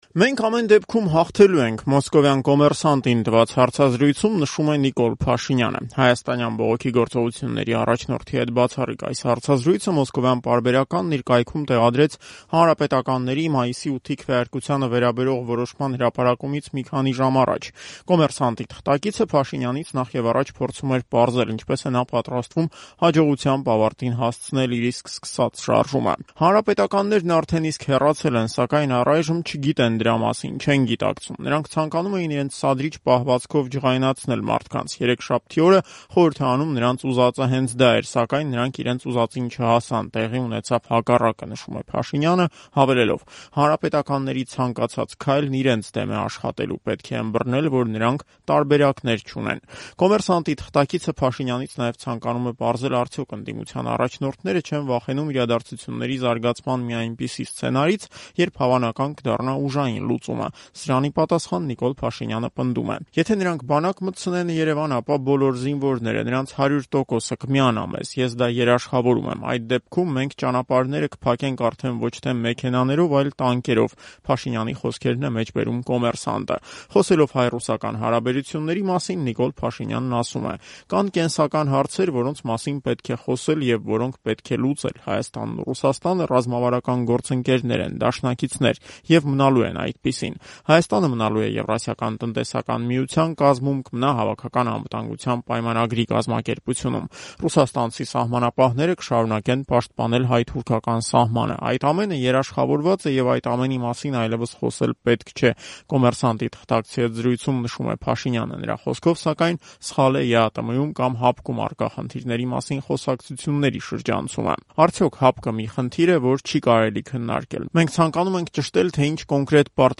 Փաշինյանի հարցազրույցը՝ «Կոմերսանտ»-ին
Ռեպորտաժներ